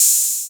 OH808D6 1.wav